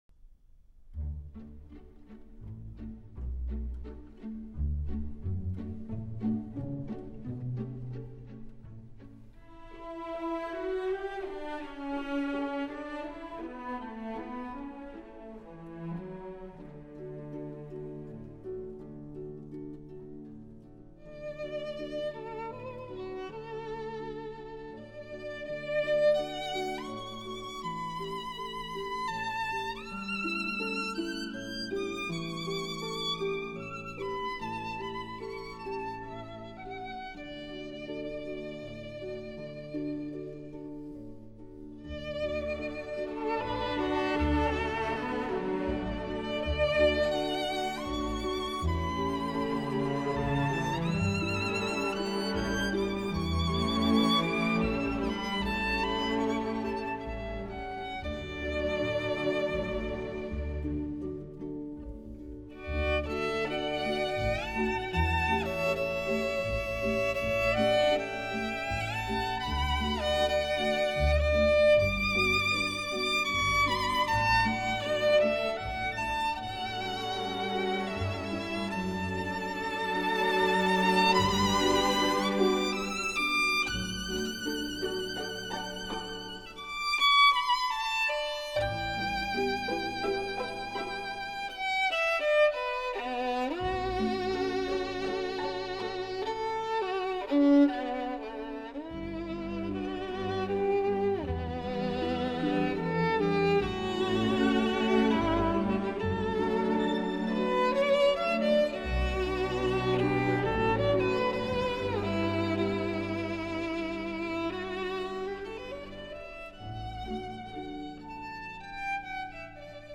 十六首发烧古典小提琴经典小品
弦乐伴奏配合得天衣无缝；录音方面，小提琴的迷人音色极具发烧元素，醇如佳酿，令所有乐迷陶醉万分。